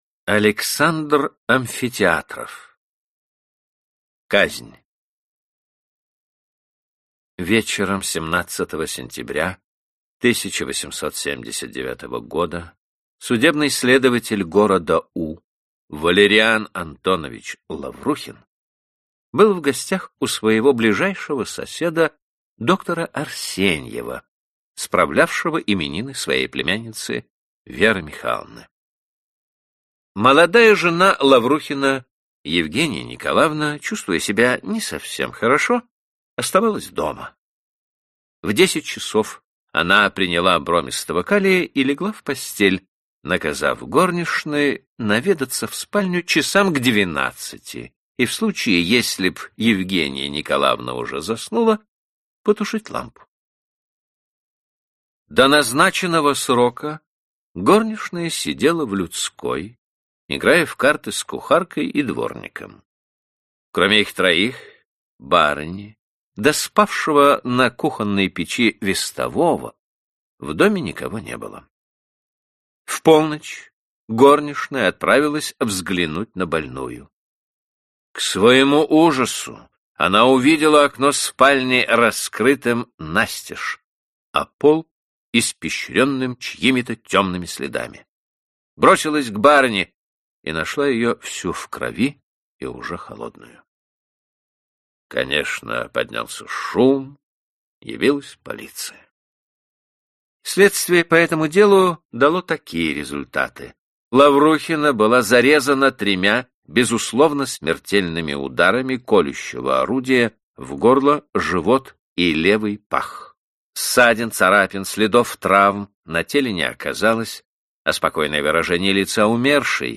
Аудиокнига Классика русского детективного рассказа № 3 | Библиотека аудиокниг